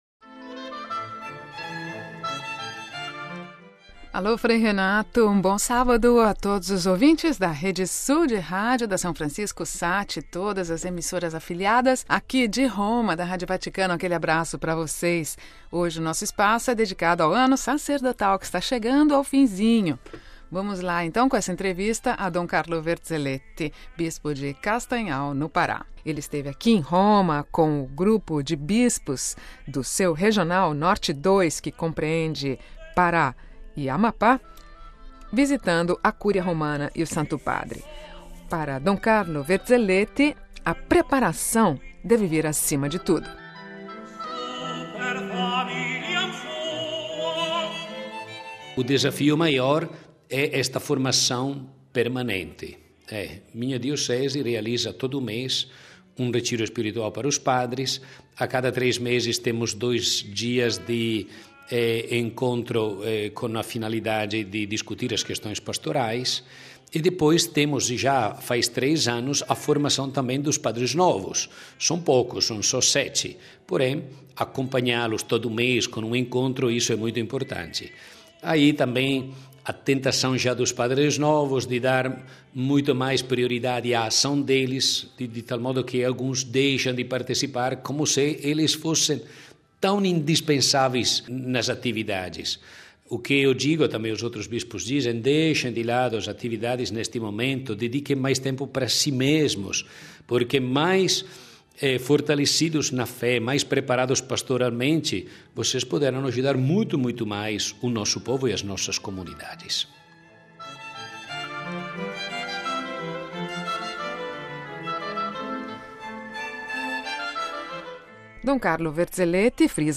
Home Archivio 2010-05-25 14:42:37 O ANO SACERDOTAL NO NORTE DO BRASIL Cidade do Vaticano, 25 mai (RV) - No Norte do Brasil, a preparação e a união do clero para enfrentar os desafios. Ouça Dom Carlo Verzelletti, Bispo de Castanhal: All the contents on this site are copyrighted ©.